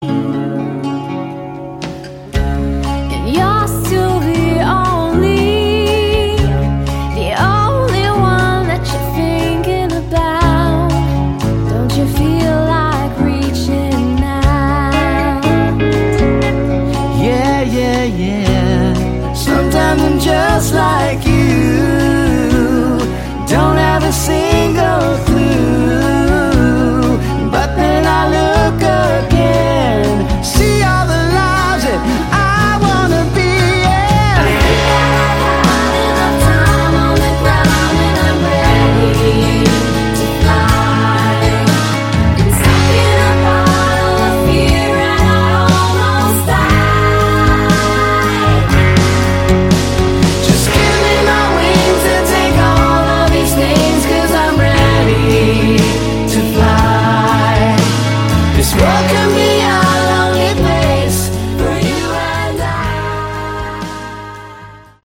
Category: AOR
lead vocals
acoustic guitar and ukulele
electric guitar
bass and vocals
drums and percussion
vocals and descant recorder